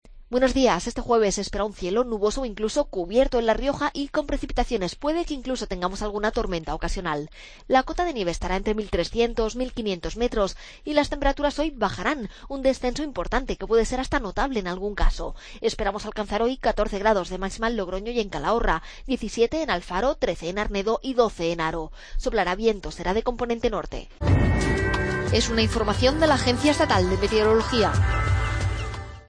AUDIO: Pronóstico. Agencia Estatal de Meteorología.